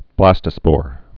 (blăstə-spôr)